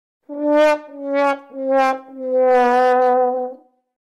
Womp Womp Womp Sound Effect: Unblocked Meme Soundboard
Play the iconic Womp Womp Womp Sound Effect for your meme soundboard!